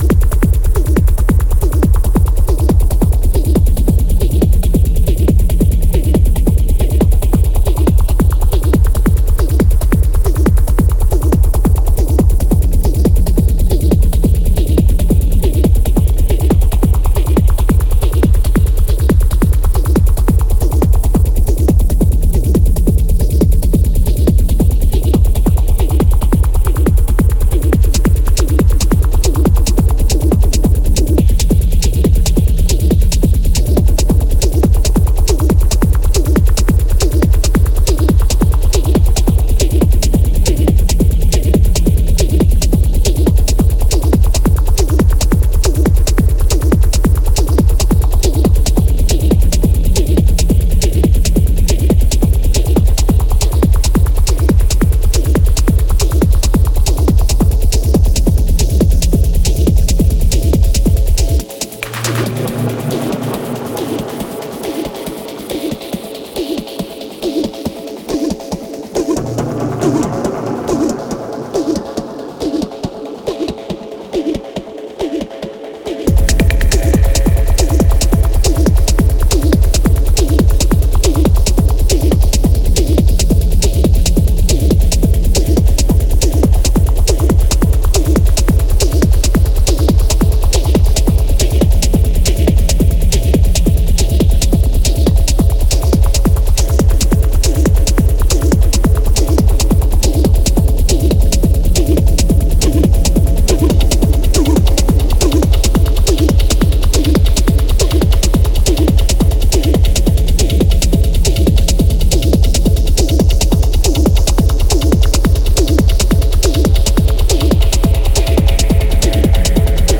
Deep drowning techno cuts